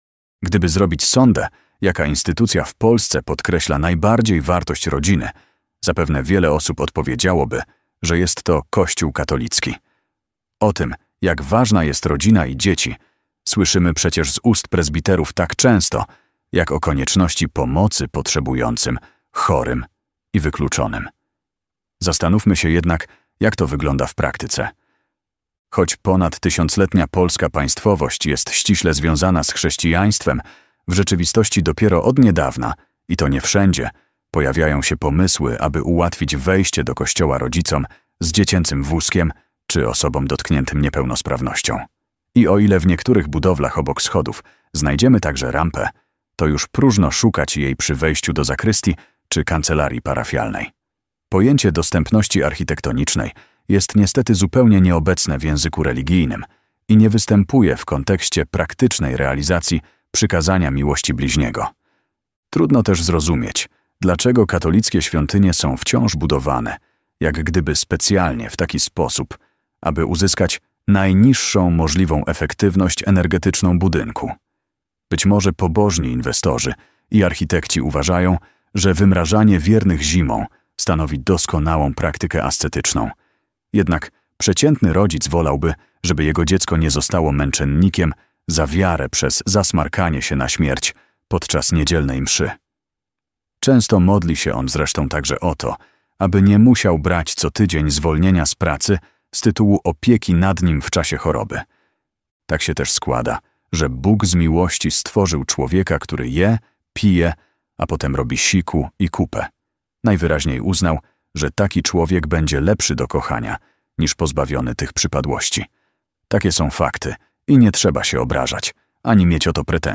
Wersja audio została nagrana przy pomocy ElevenLabs TTS.